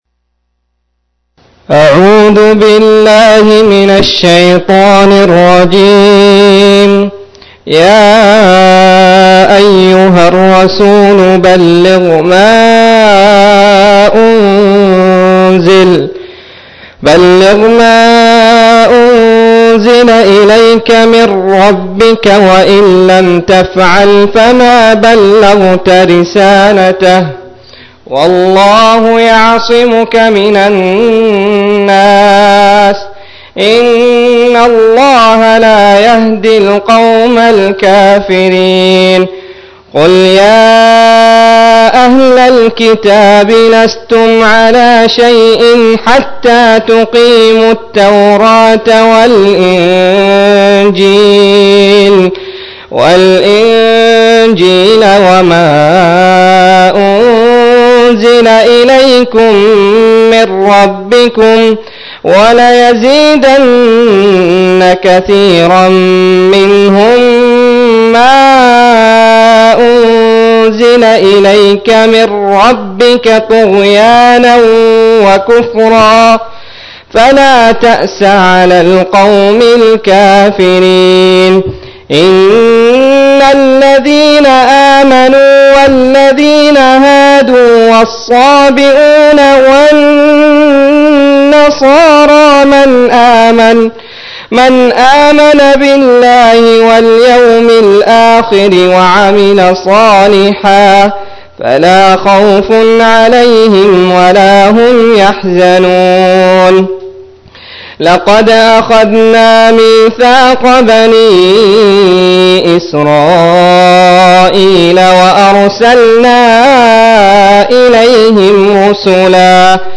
119- عمدة التفسير عن الحافظ ابن كثير رحمه الله للعلامة أحمد شاكر رحمه الله – قراءة وتعليق –